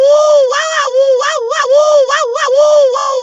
Play, download and share WAWAWOOWOO original sound button!!!!